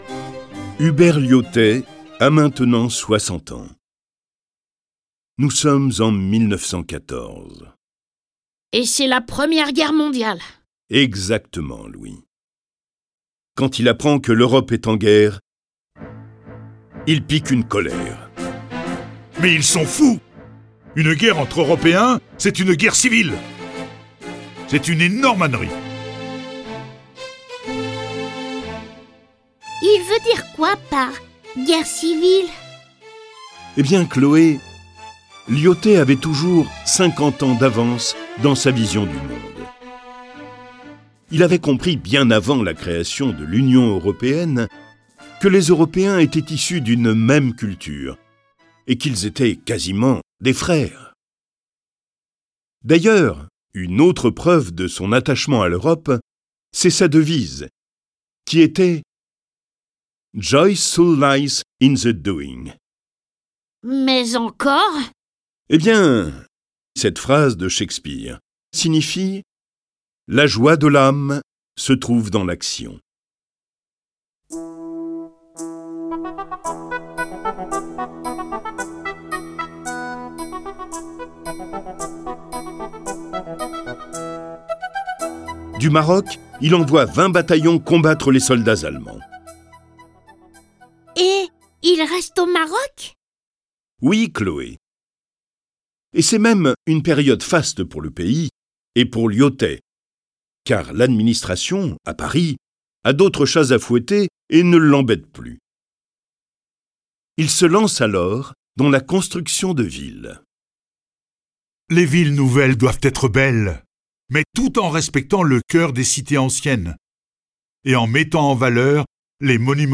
Diffusion distribution ebook et livre audio - Catalogue livres numériques
Le récit de la vie de ce grand soldat est animé par 6 voix et accompagné de près de 40 morceaux de musique classique et traditionnelle.